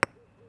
concrete1.wav